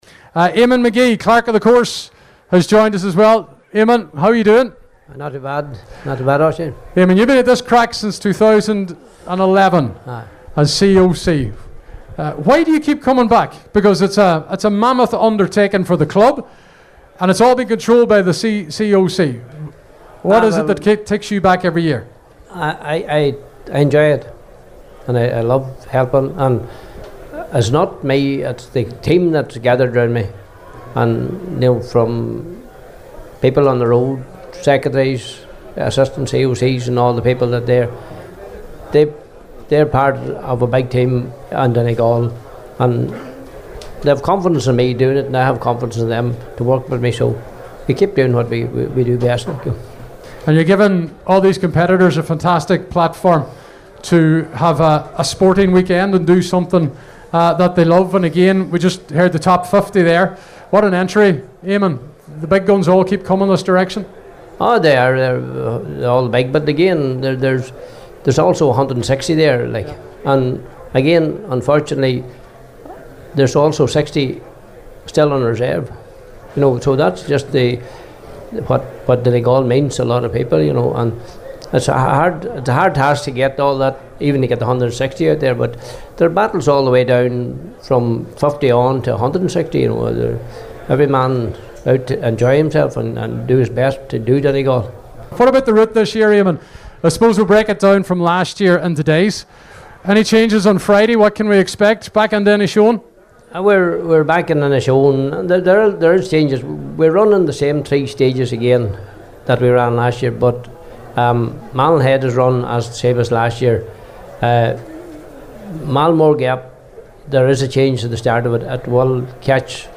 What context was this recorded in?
Donegal International Rally Launch night interviews